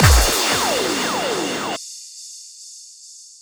VTDS2 Song Kit 10 Pitched If You Know Fill.wav